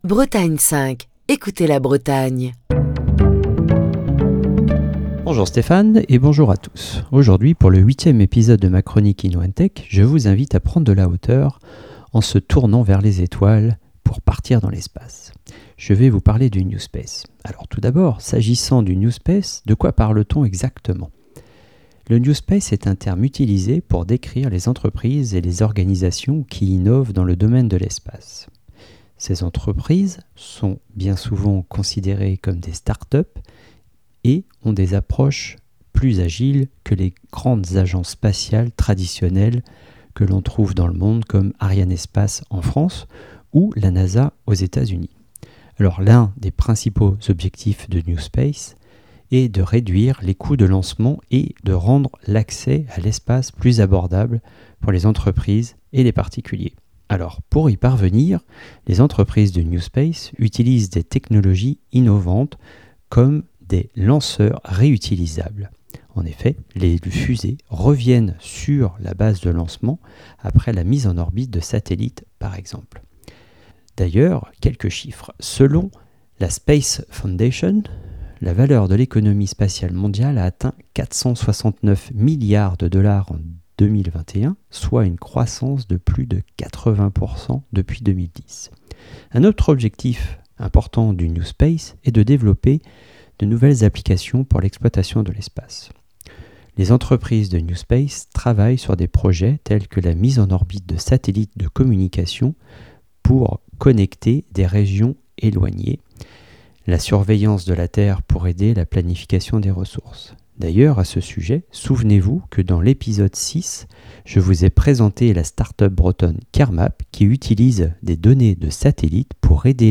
Chronique du 22 mars 2023.